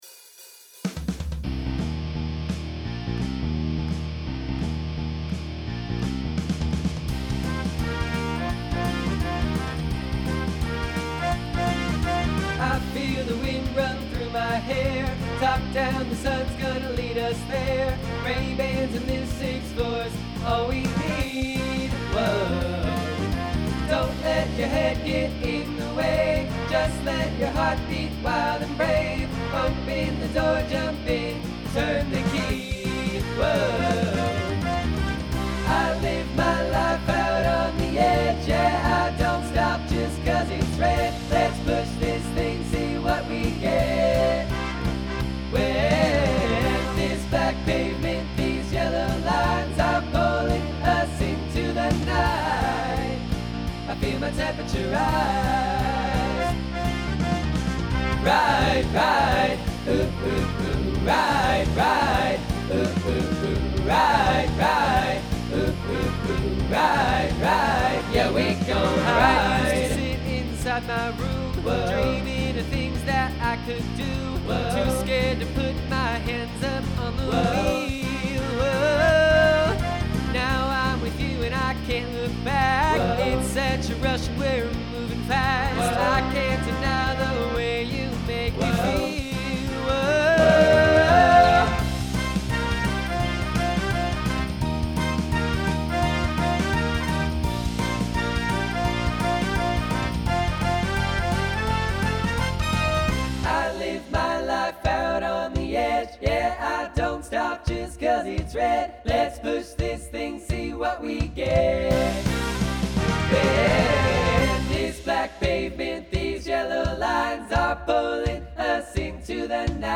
Rock
Transition Voicing TTB